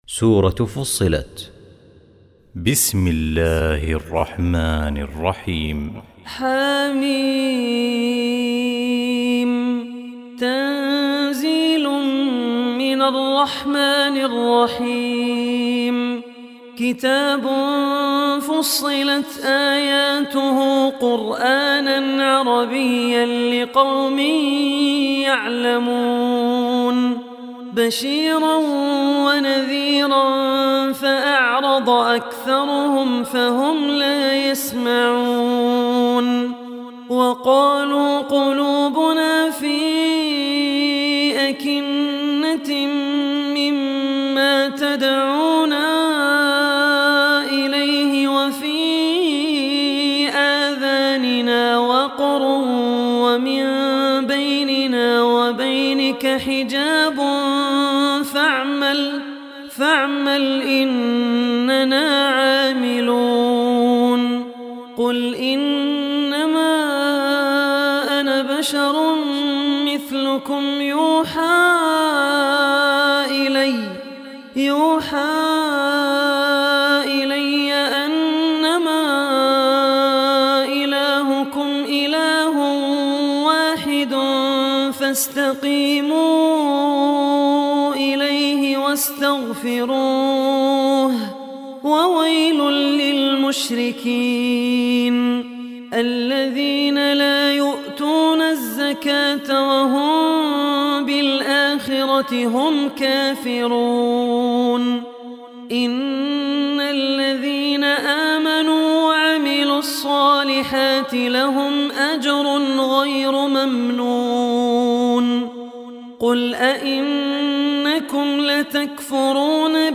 Surah Fussilat Online Recitation